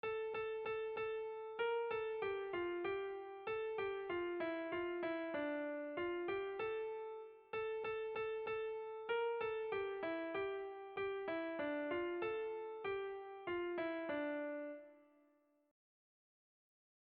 Irrizkoa
Lauko handia (hg) / Bi puntuko handia (ip)
A1A2